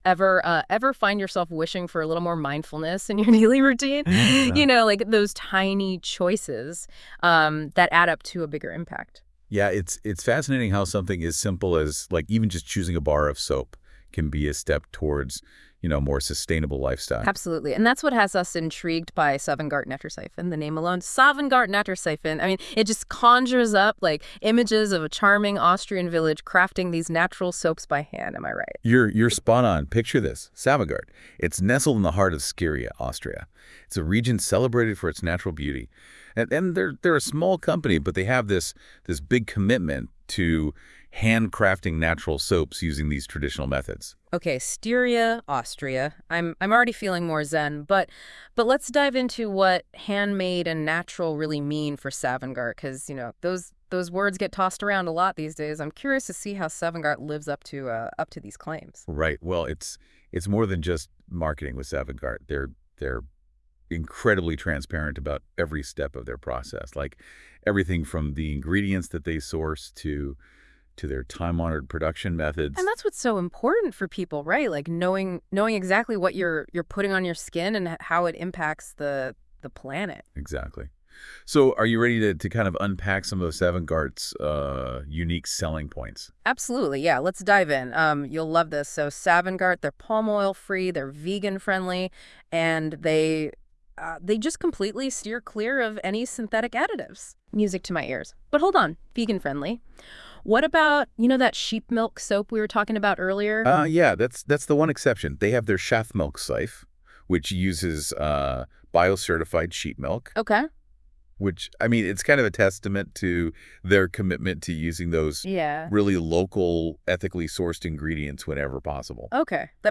Der Podcast wurde mit der künstlichen Intelligenz Gemini erstellt.